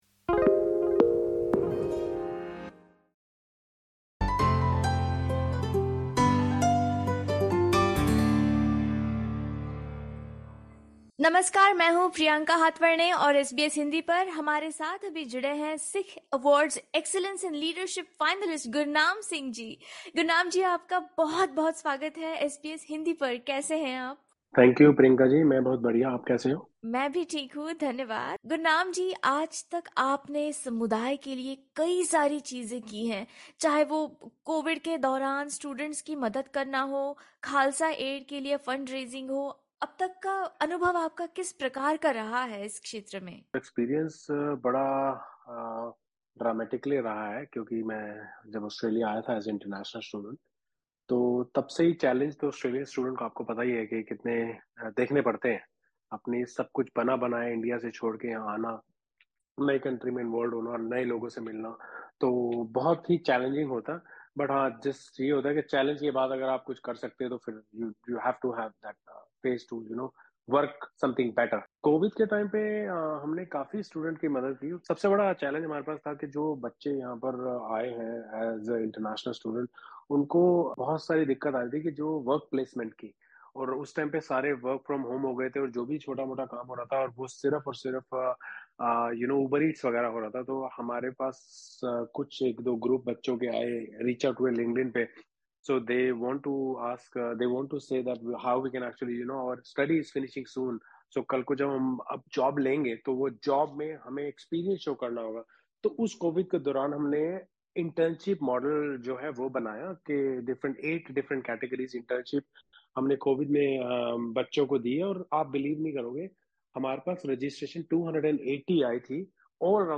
In this podcast, SBS Hindi interviews him about his journey and passion for helping others.